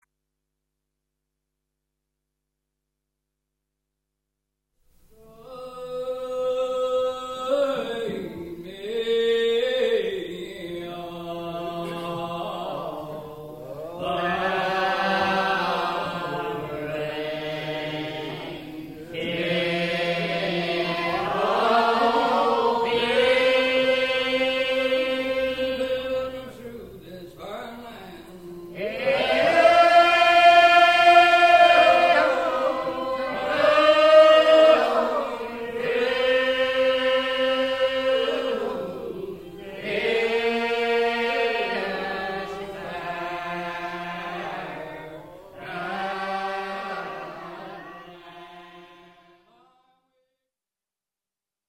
当時の歌唱法は器楽の伴奏はなく、ライン・アウト唱法といって先導者が歌詞をリードするのを追って全員が歌う方法をとっていました。
実際にこの時代の歌唱法をそのまま伝承する教派が残っていて、追分節のような小節を利かせた拍節感のない歌い方で延々とメロディを引き延ばすやり方です。
ケンタッキー州ブラッッケイのMount Olivet Regular Bptist Churchの歌唱。
チューン・ブックを元に歌唱しているが地声を張り上げる独特の歌い方である。
Bptist_Hymn.MP3